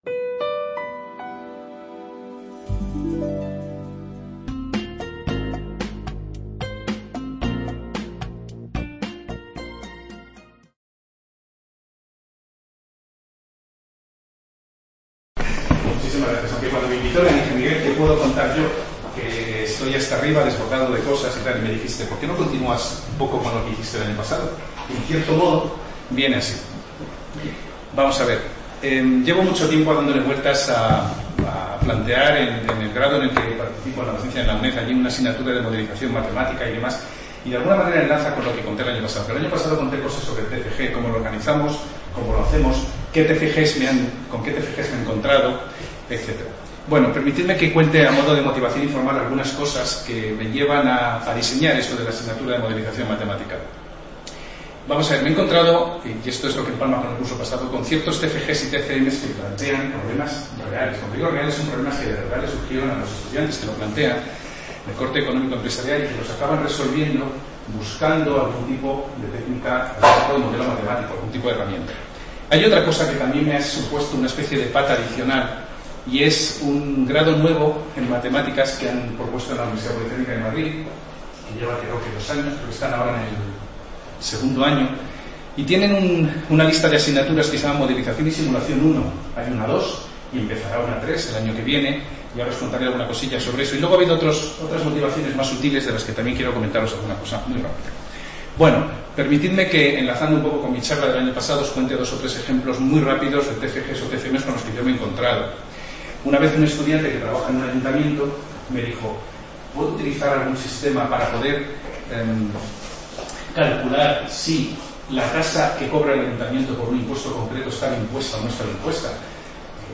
Estas jornadas se desarrollan en el Centro Asociado de la UNED de Úbeda y pretenden ser un punto de encuentro para compartir experiencias educativas reales en Estadística y/o Matemáticas que se enmarquen en el ámbito cotidiano de la docencia en los diferentes niveles de la educación Matemática, aunque está abierto a docentes de otras materias.